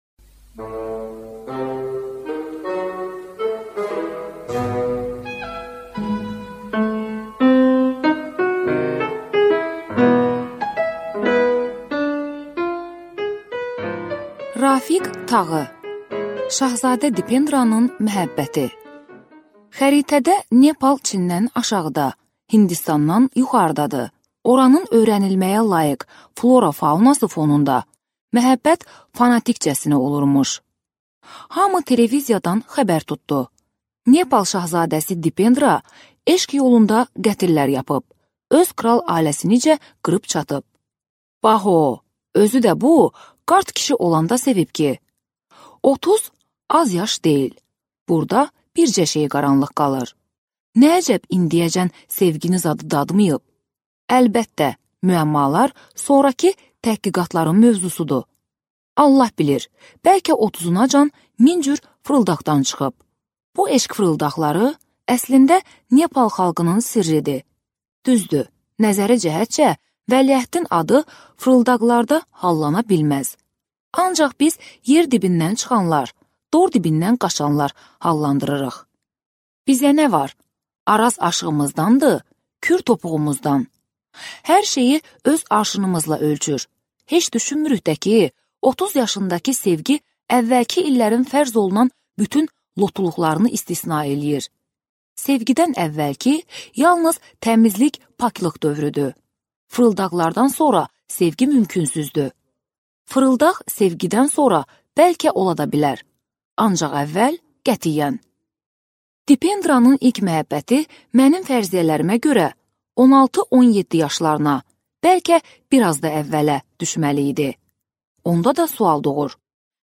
Аудиокнига Şahzadə Dipendranın məhəbbəti | Библиотека аудиокниг